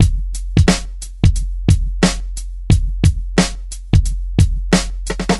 89 Bpm Breakbeat E Key.wav
Free drum groove - kick tuned to the E note. Loudest frequency: 950Hz
89-bpm-breakbeat-e-key-NIS.ogg